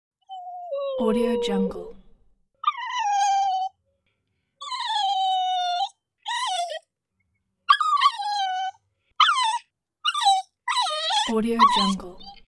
Pup Whines Téléchargement d'Effet Sonore
Pup Whines Bouton sonore